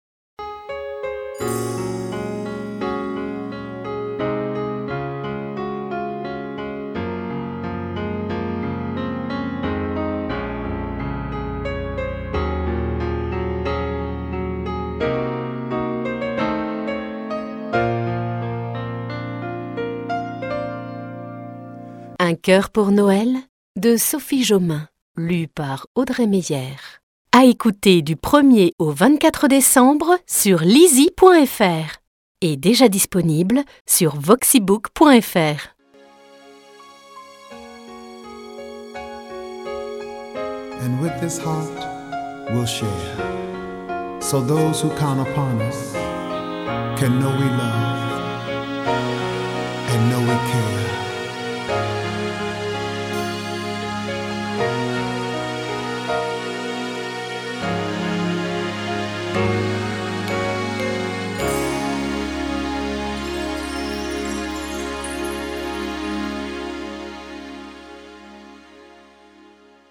Le conte de l'Avent : Casse-Noisette